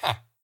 sounds / mob / villager / yes3.ogg